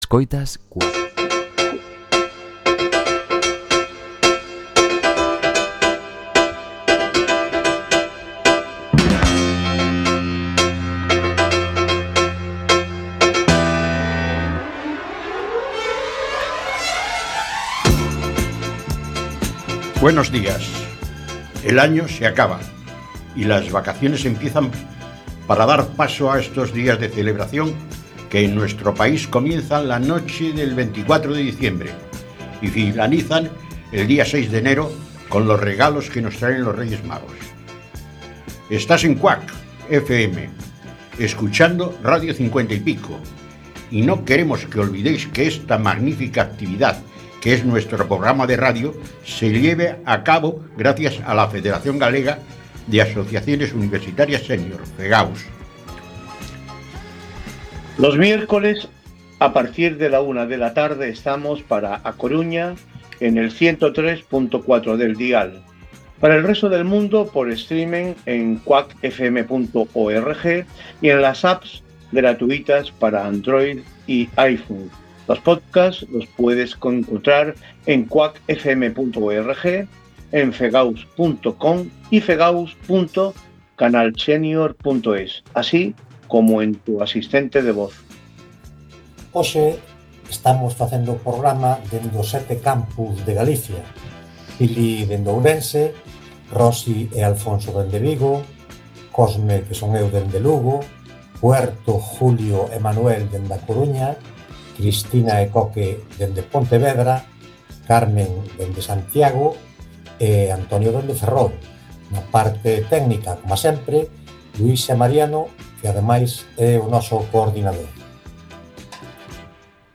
Radio 50 y Pico es un proyecto de comunicación de la Federación Gallega de Asociaciones Universitarias Senior (FEGAUS). Se realiza desde cinco de los siete campus universitarios de Galicia y aborda todo tipo de contenidos de interés, informativos, culturales, de actualidad y de entretenimiento.